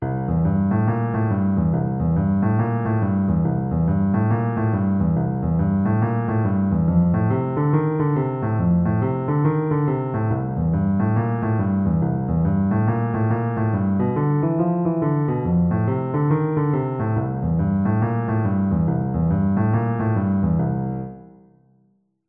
BluesPatternLeft0009.mp3